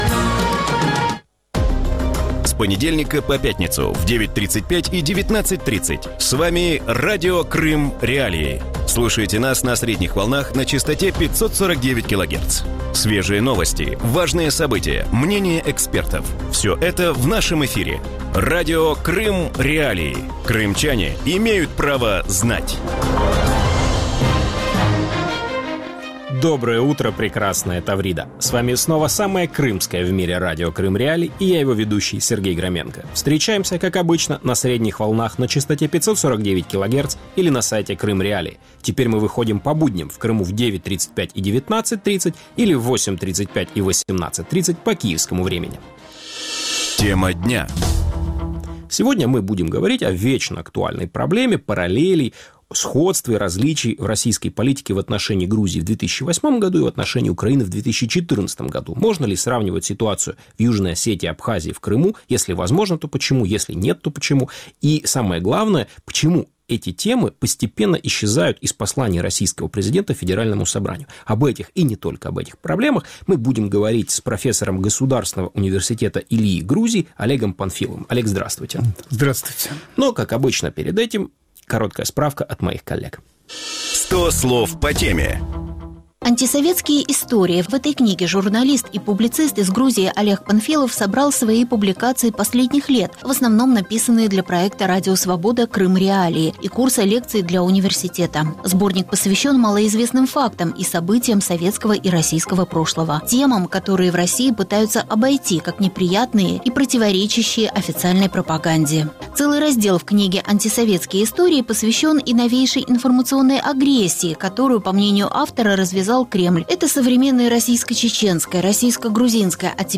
Утром в эфире Радио Крым.Реалии говорят о том, что общего и что различного в российской политике в отношении Грузии в 2008-м и в отношении Украины в 2014 году. Можно ли сравнивать ситуацию в Южной Осетии, Абхазии и в Крыму? Почему темы Абхазии, Южной Осетии и Крыма постепенно исчезают из посланий российского президента Федеральному собранию?